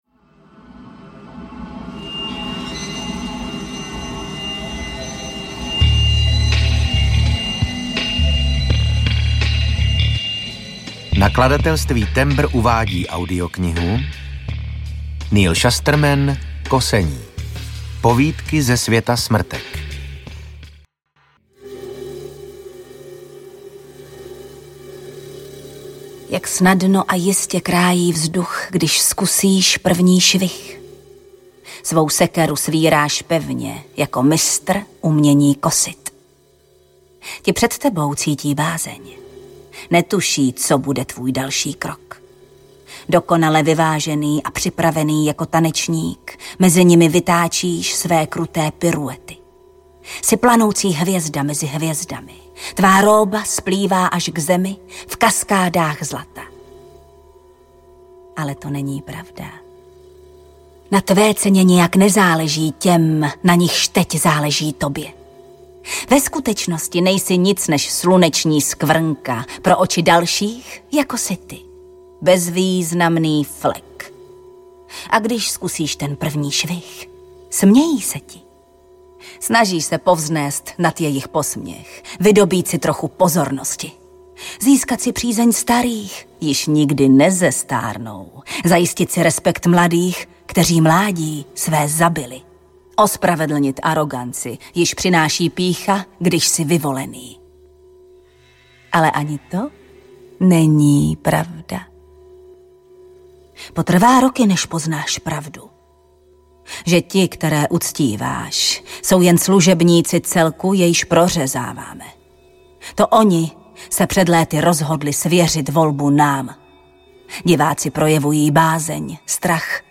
Kosení audiokniha
Ukázka z knihy
• InterpretJana Stryková, Vasil Fridrich